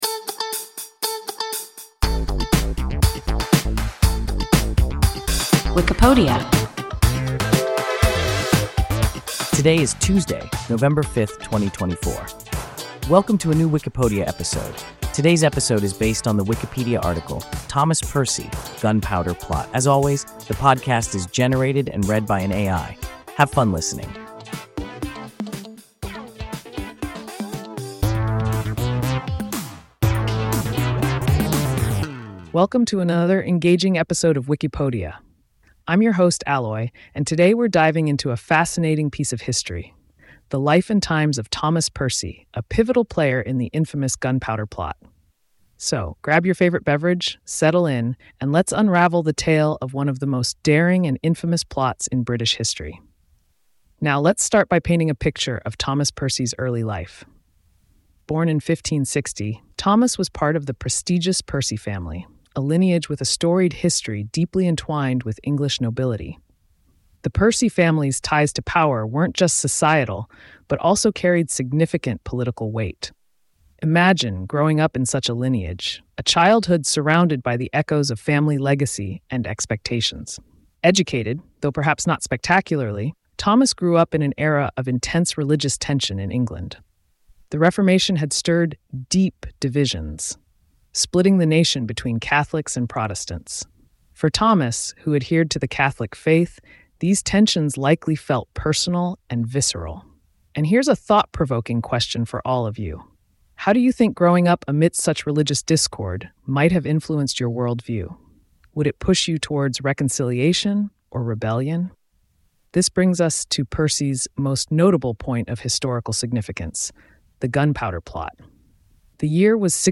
Thomas Percy (Gunpowder Plot) – WIKIPODIA – ein KI Podcast